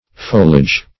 Foliage \Fo"li*age\, n. [OF. foillage, fueillage, F. feuillage,